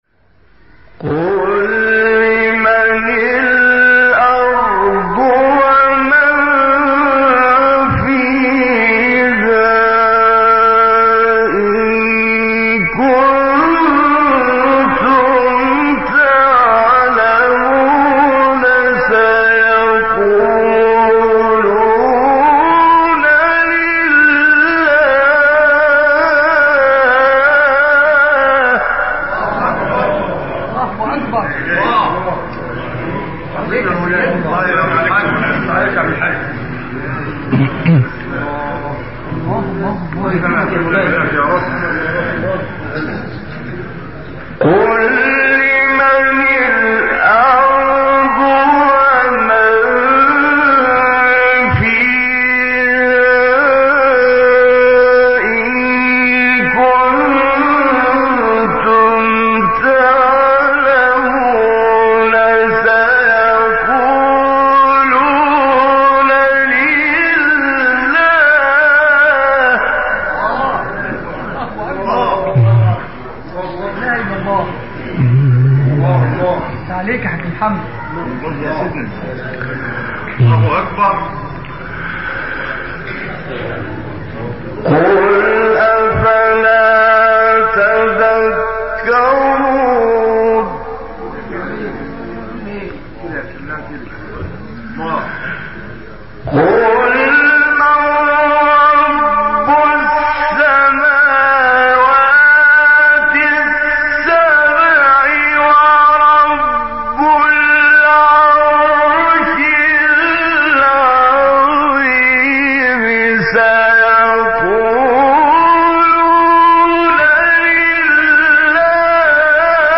تلاوت 84-87 سوره مومنون استاد عبدالوهاب طنطاوی | نغمات قرآن
سوره : مومنون آیه : 84-87 استاد : طنطاوی مقام : مرکب خوانی ( رست * بیات) قبلی بعدی